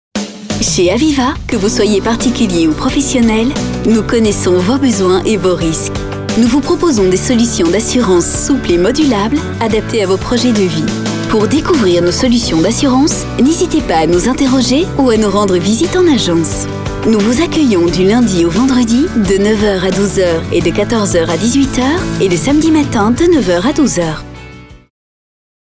Sprechprobe: Sonstiges (Muttersprache):
I adapt my voice to your needs. sweet, warm, young, serious, right, sensual, funny ...